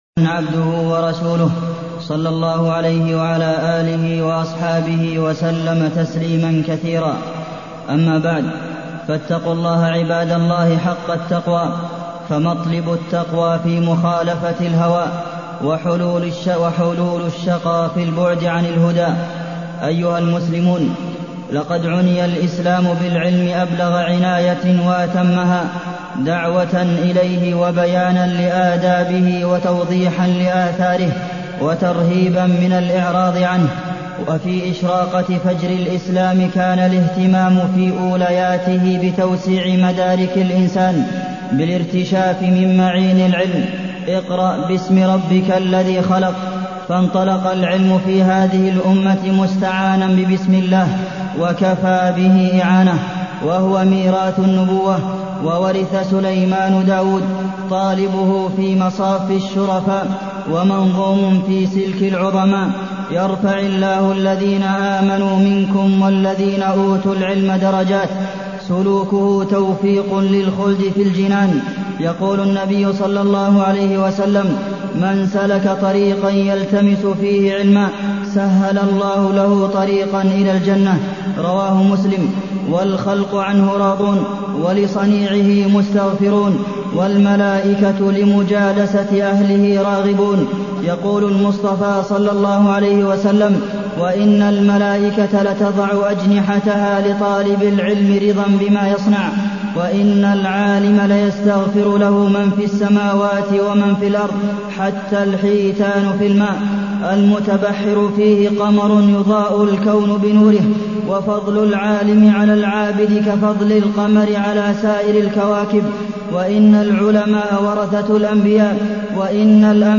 تاريخ النشر ٣ جمادى الآخرة ١٤٢١ هـ المكان: المسجد النبوي الشيخ: فضيلة الشيخ د. عبدالمحسن بن محمد القاسم فضيلة الشيخ د. عبدالمحسن بن محمد القاسم نصائح وتوجيهات لطلاب العلم والمعلمين